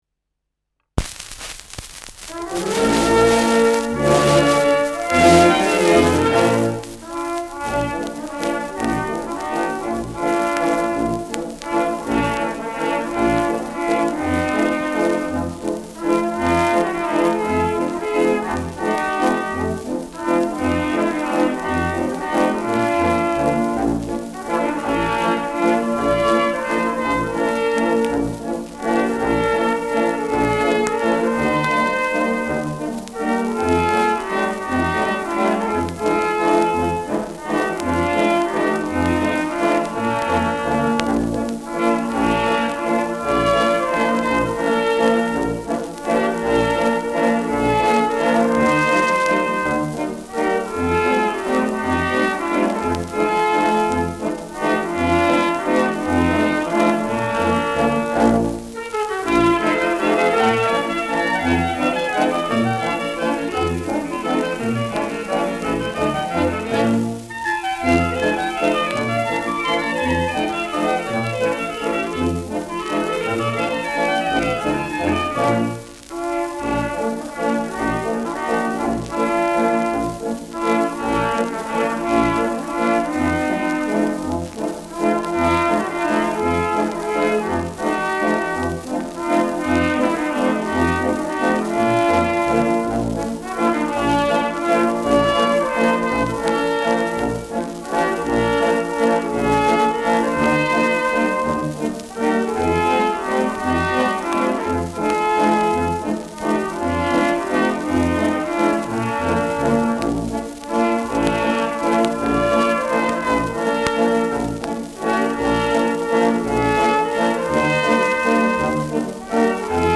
Schellackplatte
leichtes Rauschen : Knistern
[München] (Aufnahmeort)